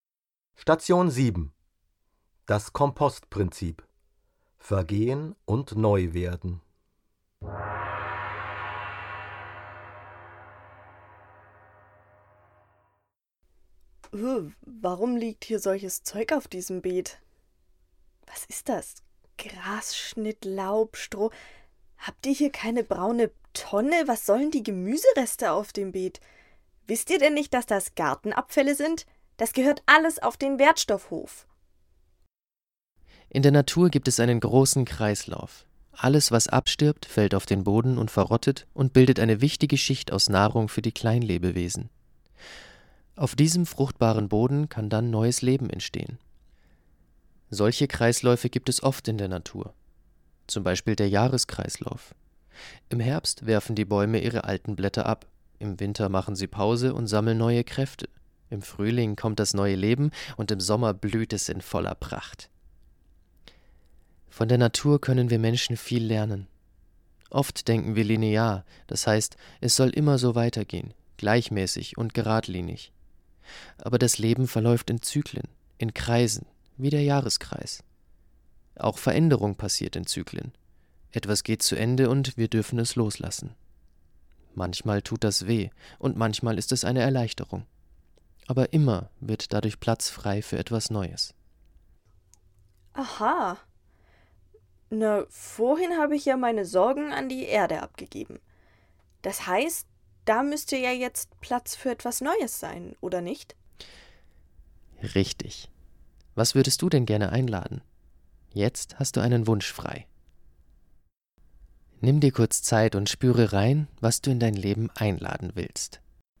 Im Permakultur-Garten entstand ein Lehrpfad und Hörpfad.